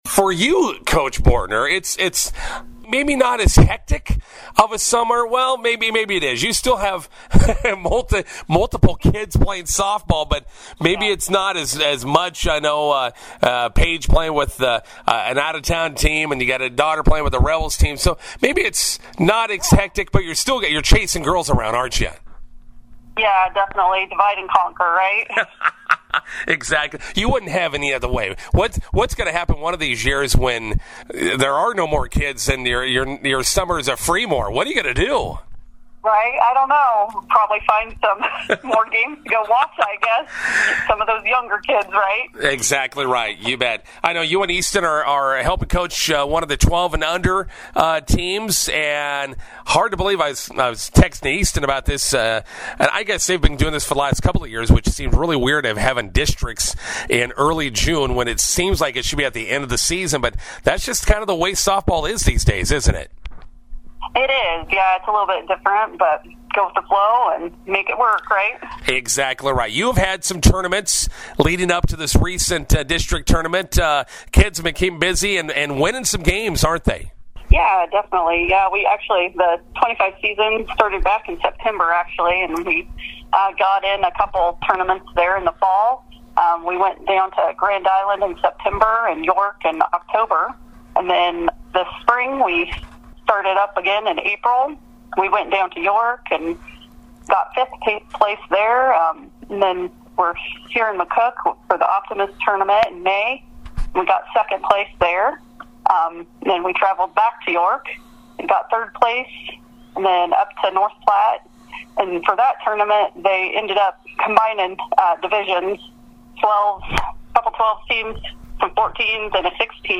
INTERVIEW: Rebels 12s win district title in Hastings over the weekend.